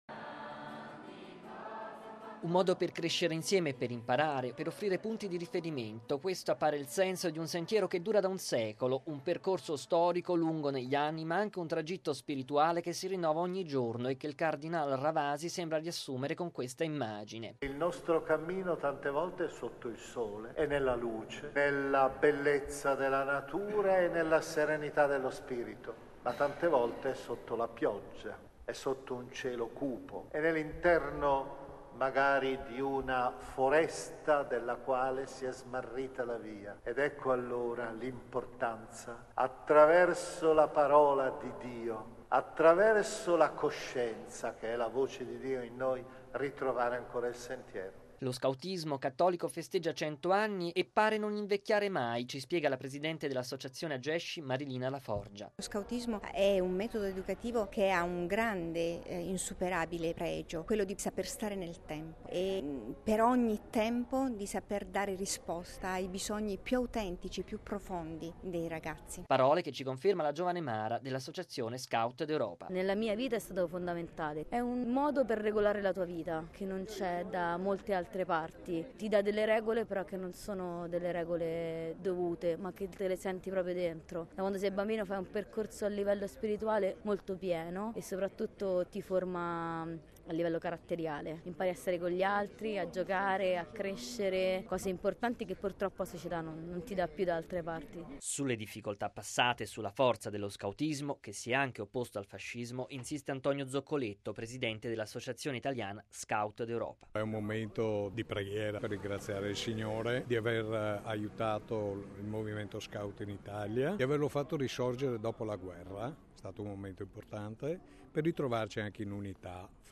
“La scelta dello scautismo è anche una vocazione di vita”, lo afferma il cardinale Gianfranco Ravasi che, a Roma, nella Basilica di San Giorgio al Velabro, ha celebrato la Messa per festeggiare i 100 anni dalla nascita dello scautismo cattolico italiano. Alla cerimonia erano presenti i vertici delle associazioni Agesci, Scout D’Europa e Masci e molti giovani scout che hanno animato questa festa con il canto.